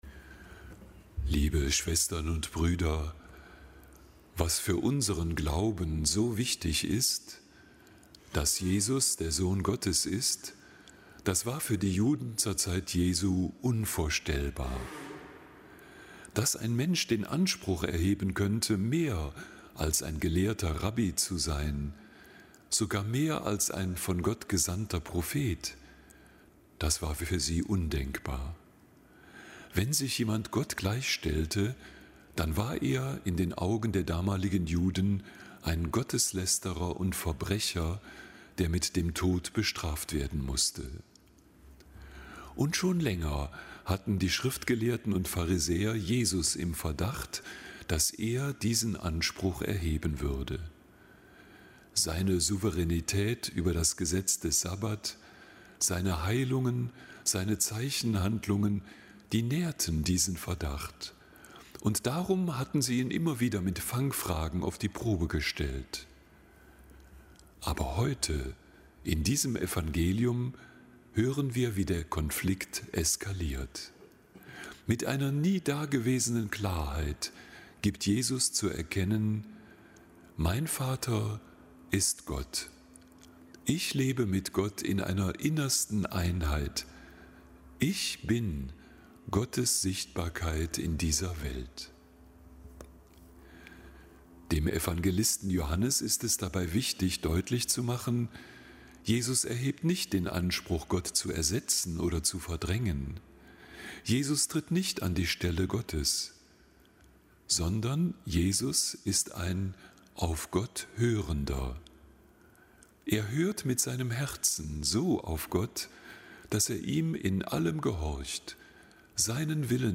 Kapitelsmesse aus dem Kölner Dom am Mittwoch der vierten Fastenwoche.
Zelebrant: Weihbischof Ansgar Puff.